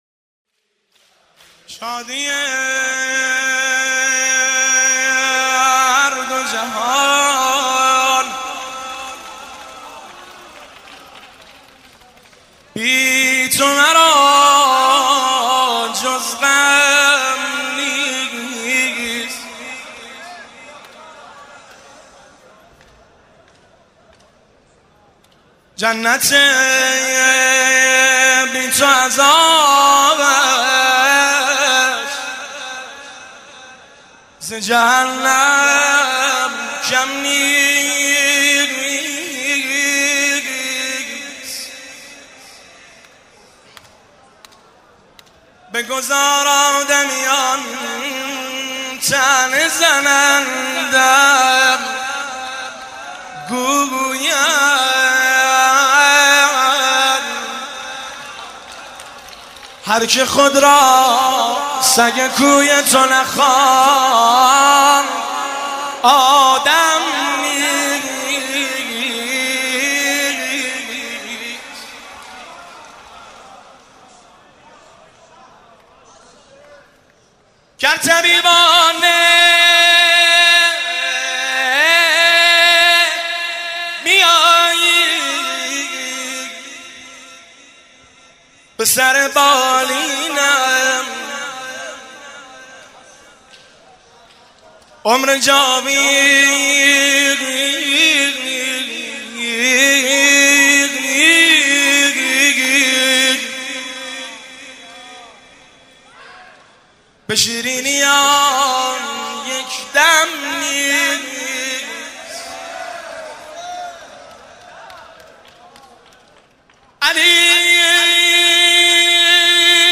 زمزمه شب 21 رمضان المبارک 1393
هیئت بین الحرمین طهران